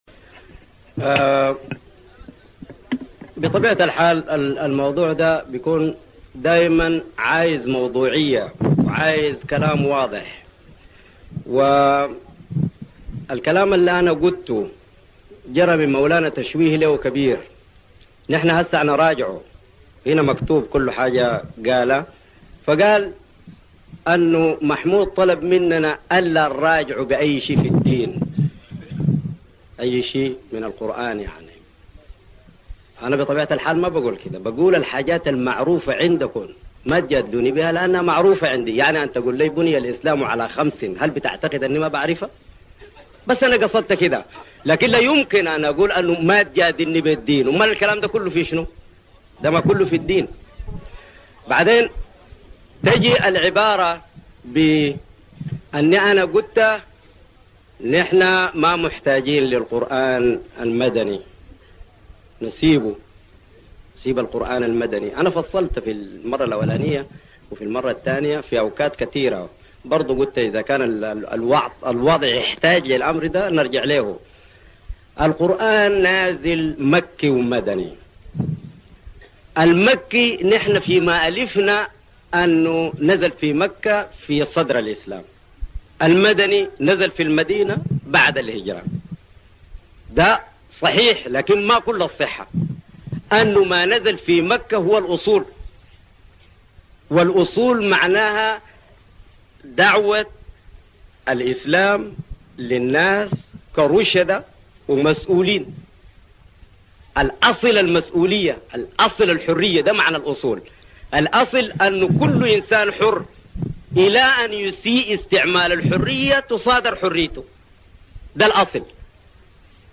تطوير شريعة الأحوال الشخصية نادي حي النصر - كوستي